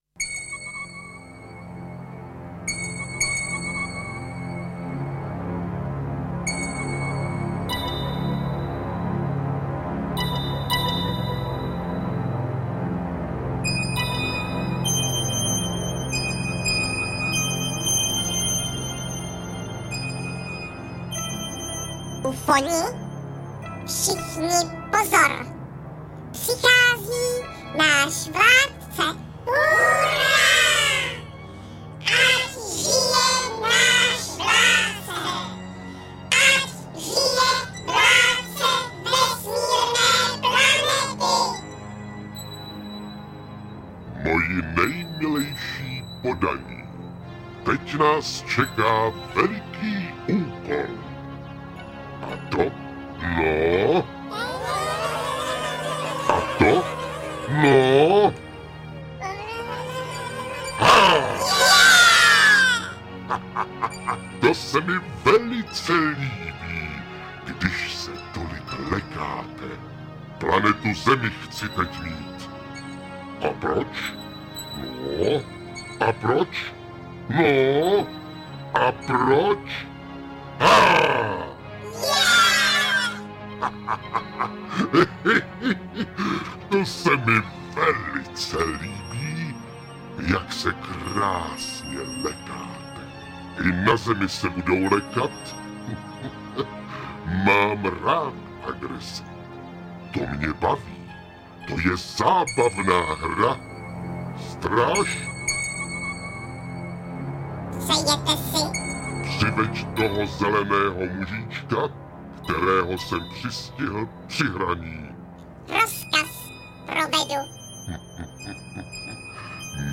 Komplet obsahuje pět titulů Divadla Spejbla a Hurvínka vydaných samostatně v letech 1998–2008 Sestava přináší nahrávky s interprety Helenou Štáchovou a Martinem Kláskem. Hurvínek Ufonem je dětská sci-fi s písničkami, ve které jsou Hurvínek s Máničkou uneseni mimozemšťany na jinou planetu a prožijí zde napínavé dobrodružství.